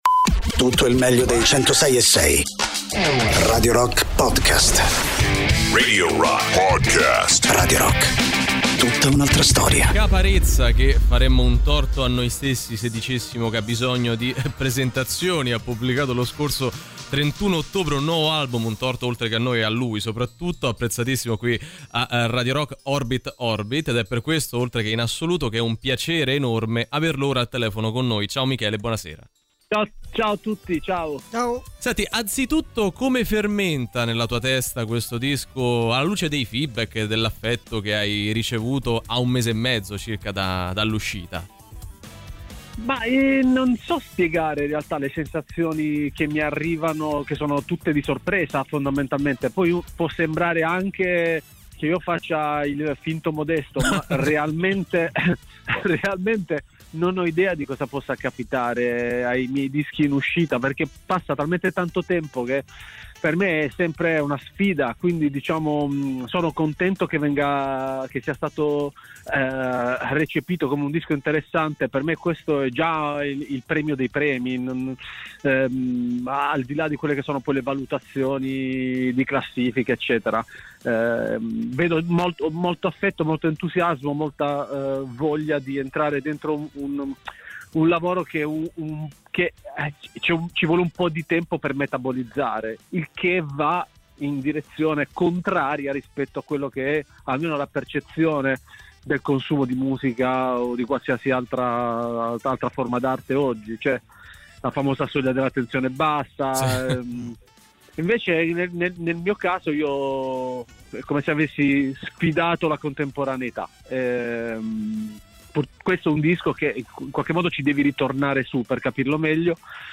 Intervista: Caparezza (17-12-25)